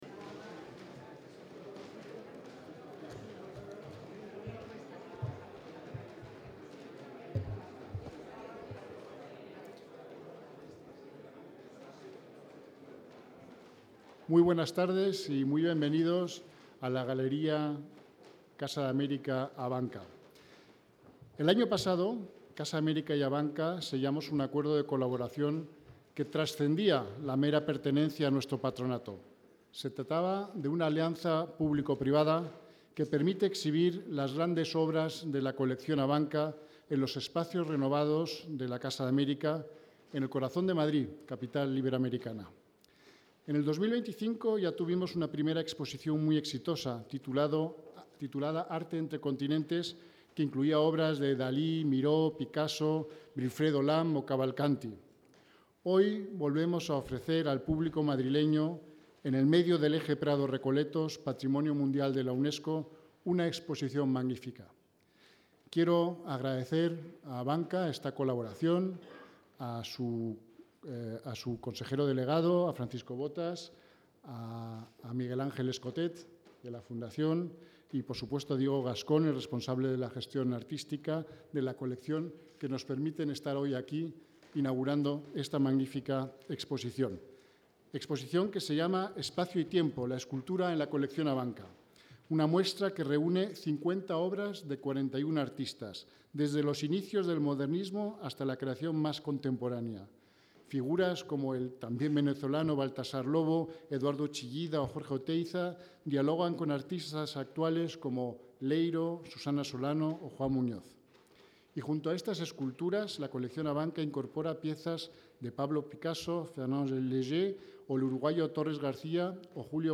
Audio de la inauguración